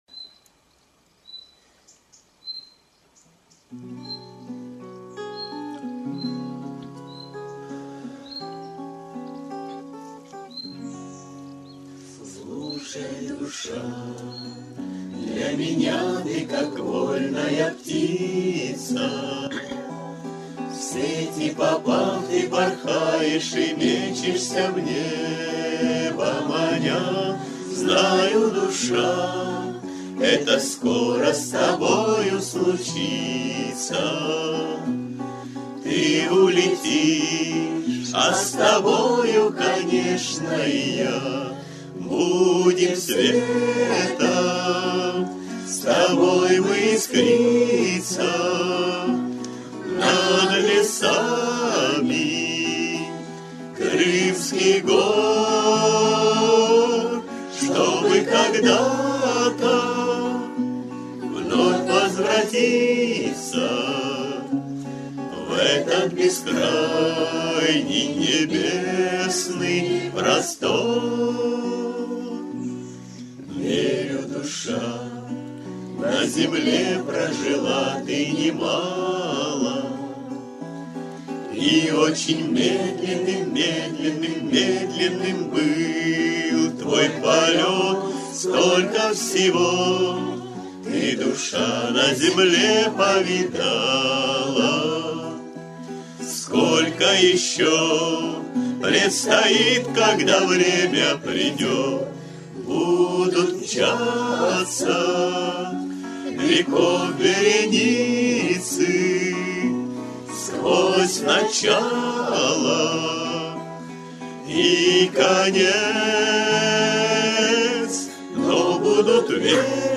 кавер-версия
акапелла